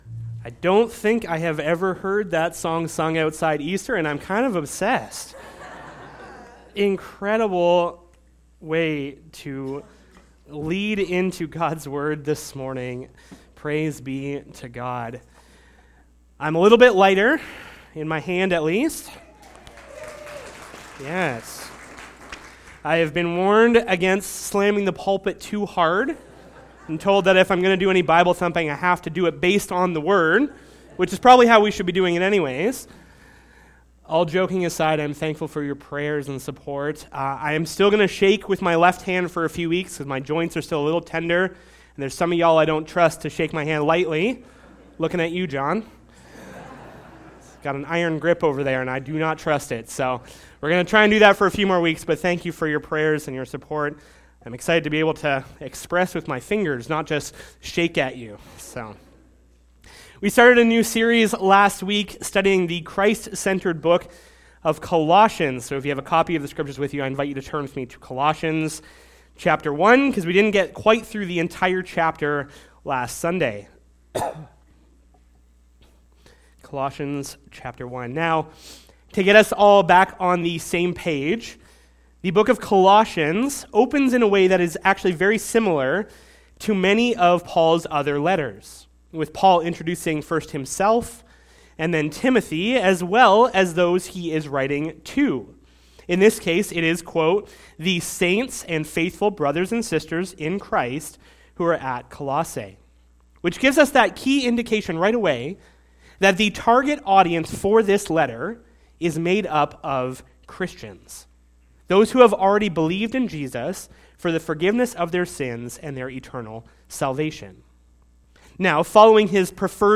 Sermon Audio and Video God's Mystery